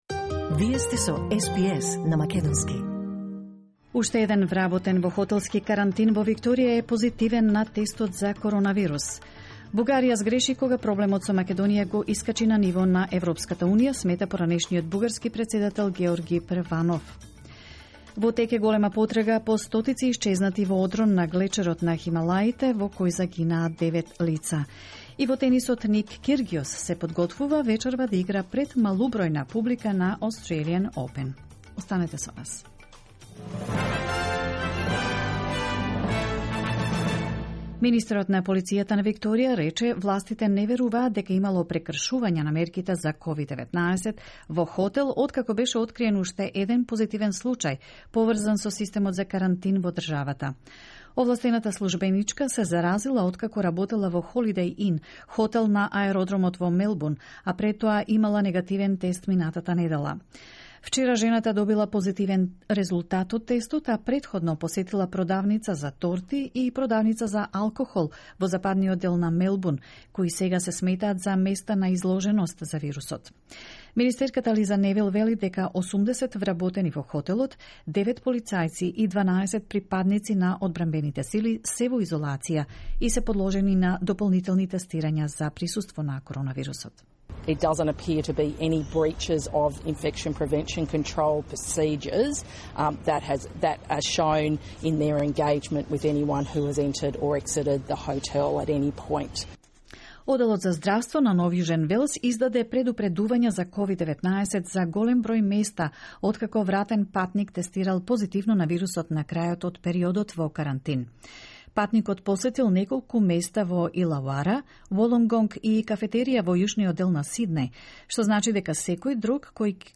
SBS News in Macedonian 8 February 2021